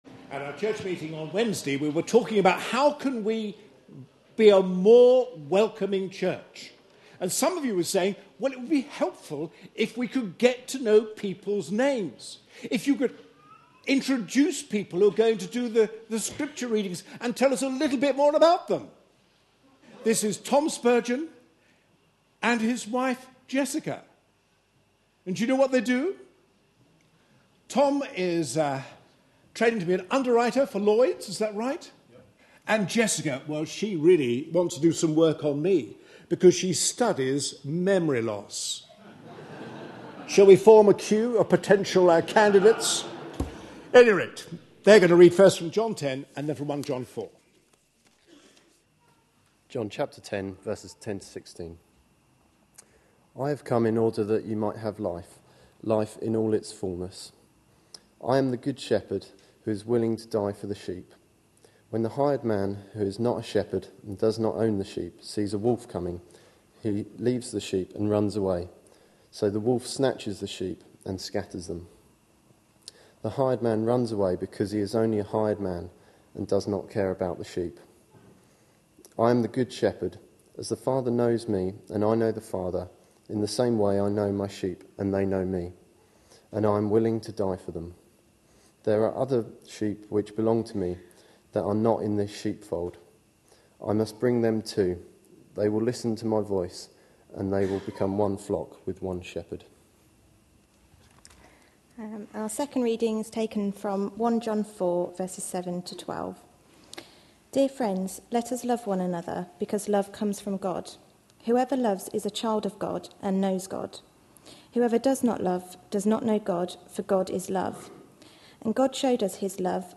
A sermon preached on 12th February, 2012, as part of our Looking For Love (10am Series) series.